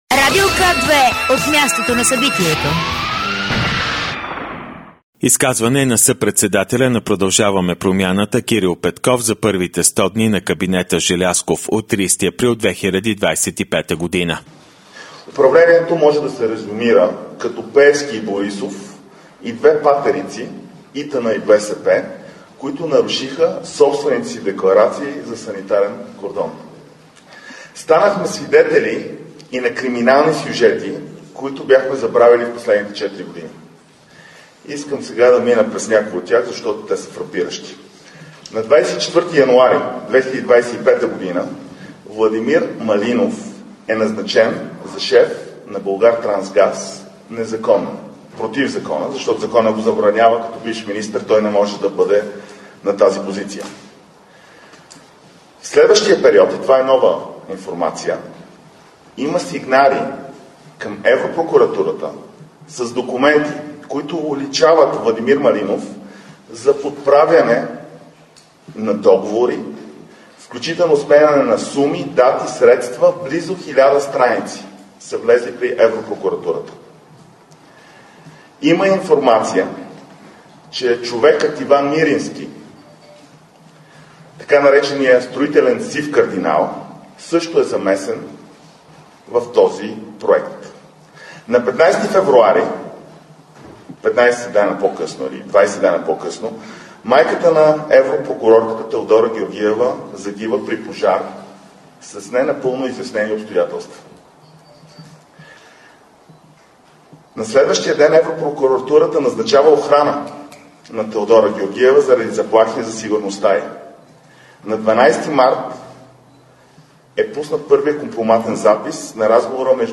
Изказване на председателя на ГЕРБ Бойко Борисов и на Мария Габриел от конгреса на ЕНП във Валенсия, от 30.04.2025